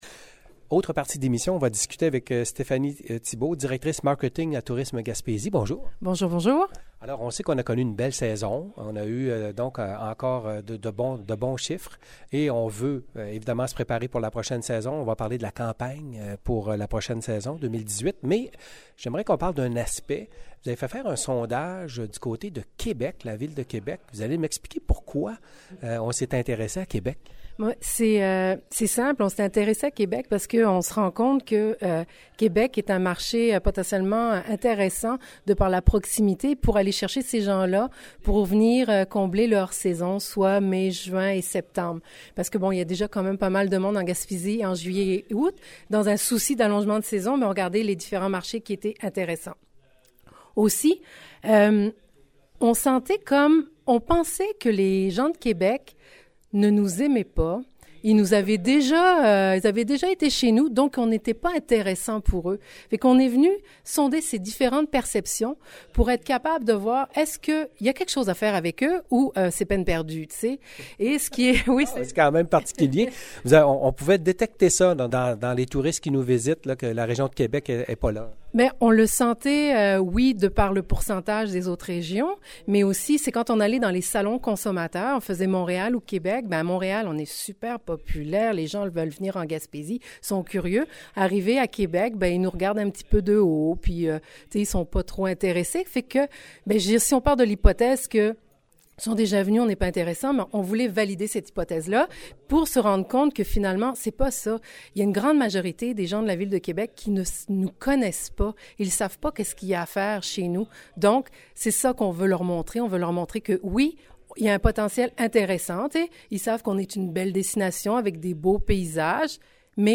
Entrevue avec la directrice du marketing